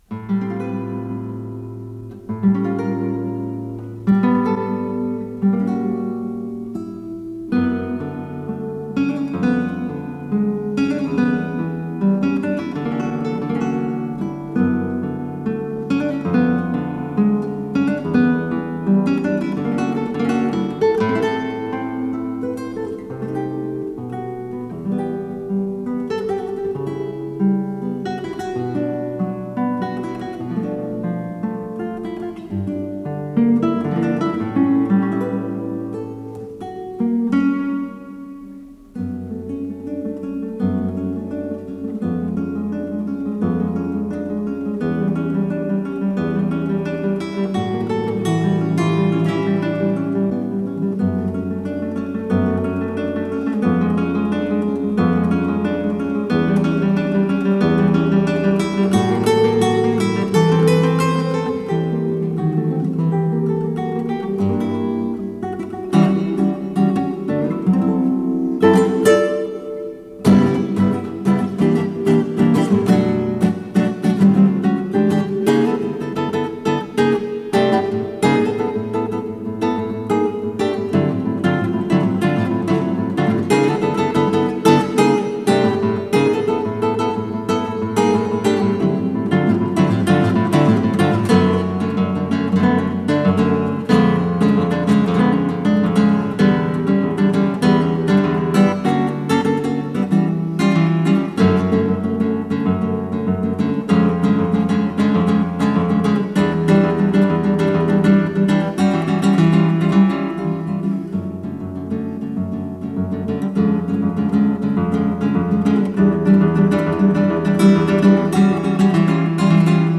с профессиональной магнитной ленты
шестиструнная гитара